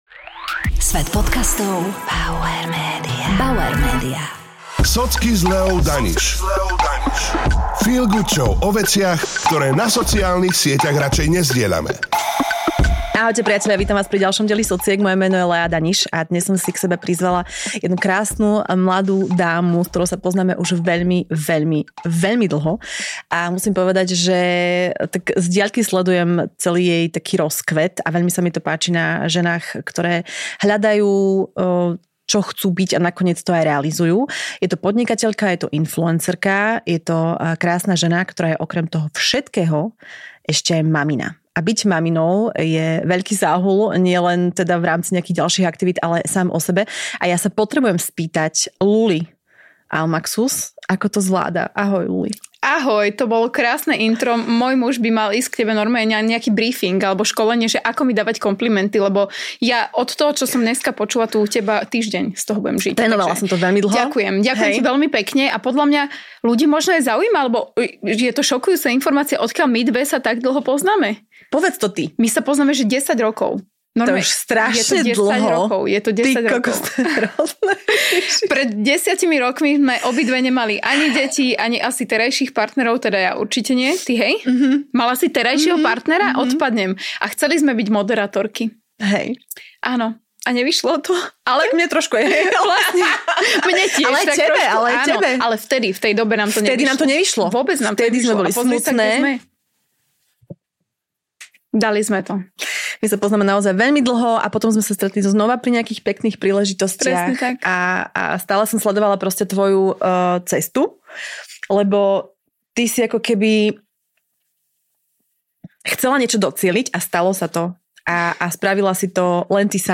Čaká ťa autentický a inšpiratívny rozhovor plný reálnych zážitkov a myšlienok, ktoré rezonujú s každou ženou, ktorá sa snaží zladiť materstvo, svoju kariéru a samu seba.